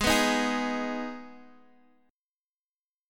Abm Chord
Listen to Abm strummed